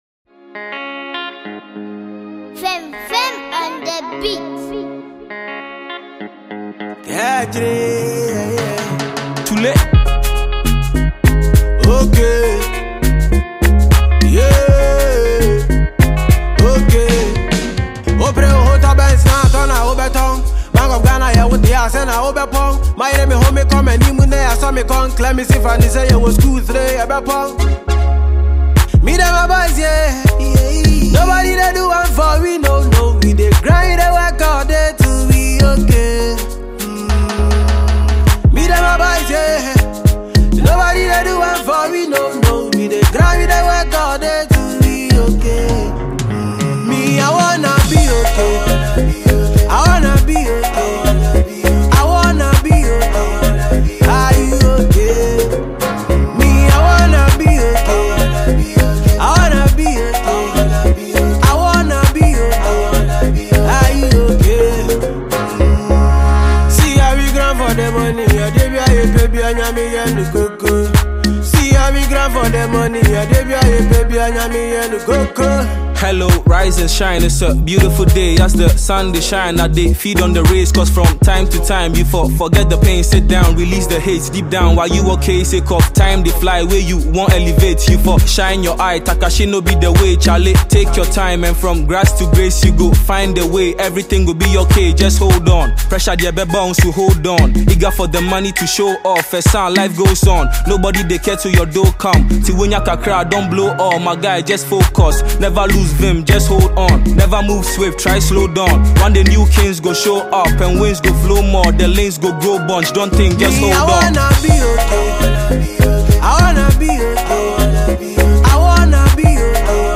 GHANA MUSIC
Ghanaian young talented singer
Ghanaian multi-talented rapper